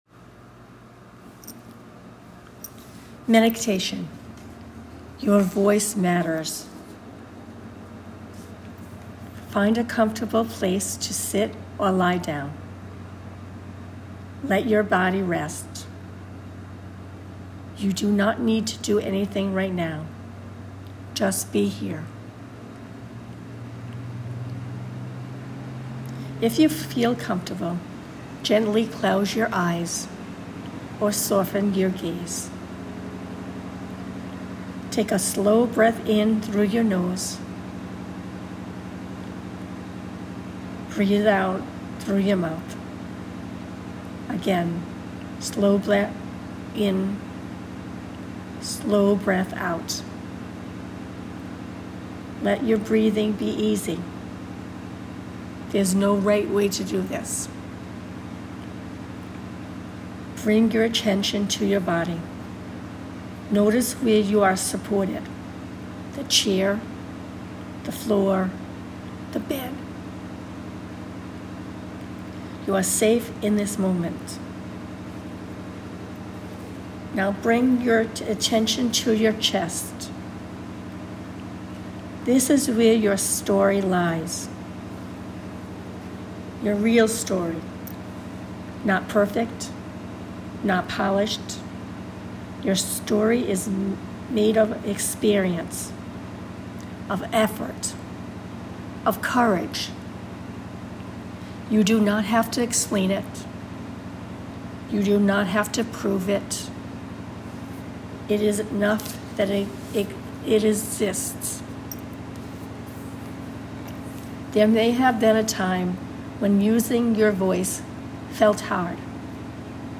Here is a meditation for you to read and record